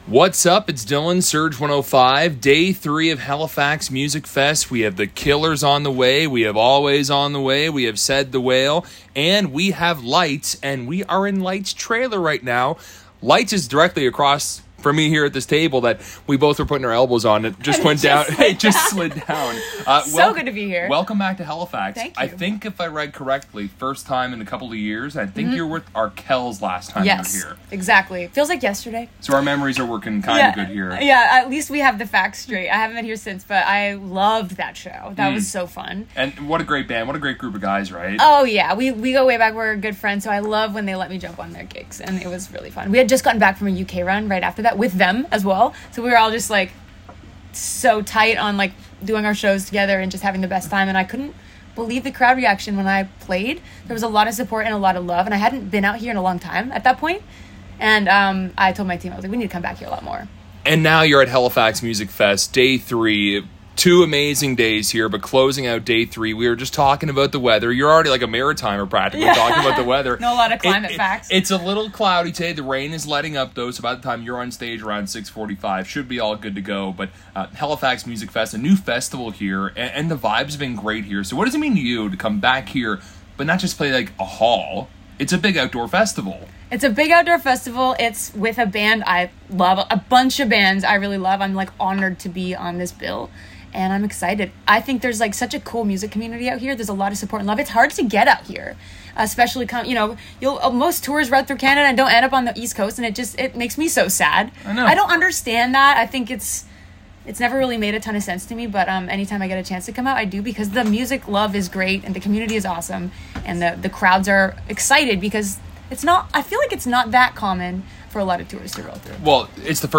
Before Day 3 kicked off, we had the chance to sit down and have a chat with Lights (Who played along with Said The Whale, Alvvays and The Killers).